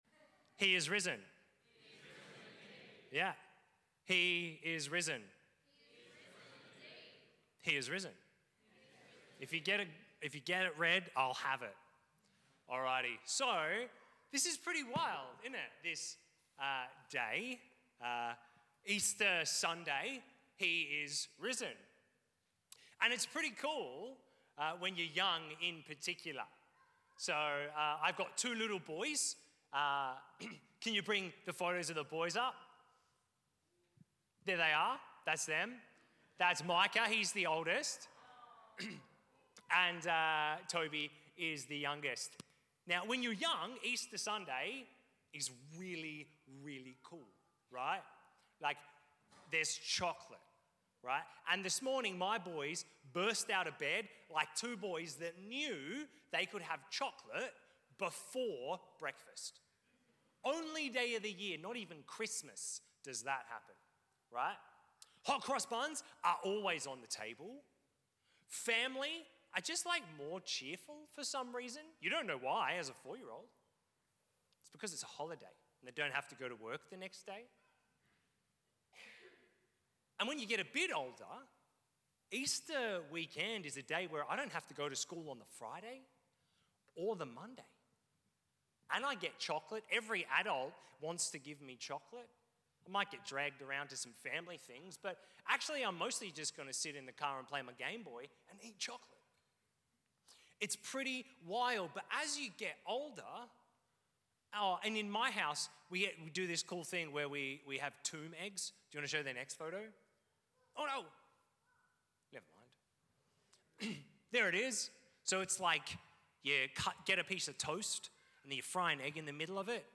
Sermons | St Hilary's Anglican Church